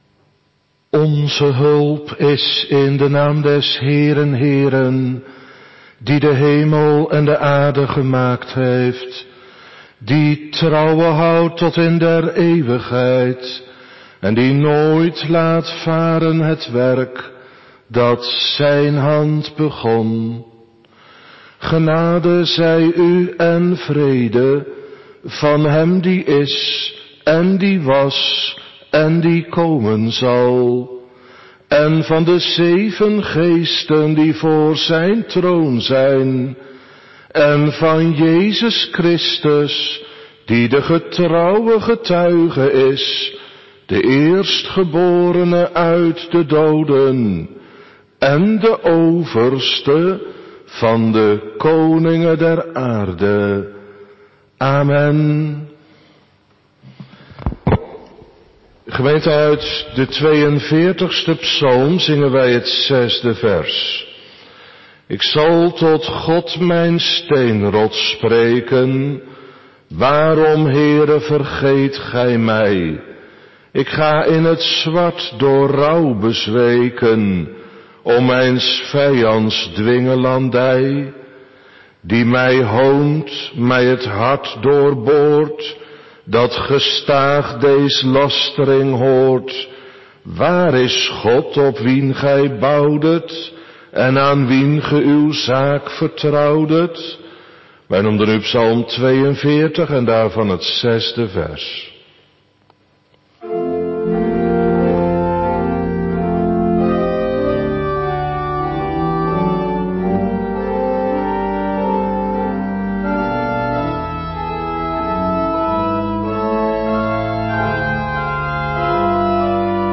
Avonddienst